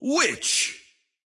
Witch_intense_2.wav